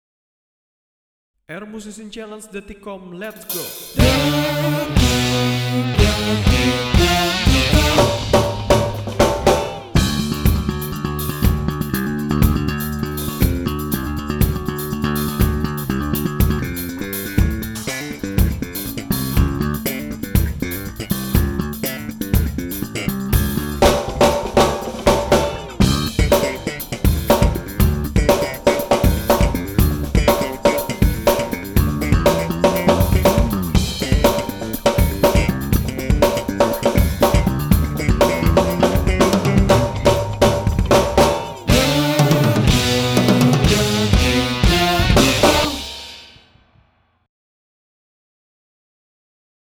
1. Kamu harus download lagu pengiring terlebih dulu.
- Bass klik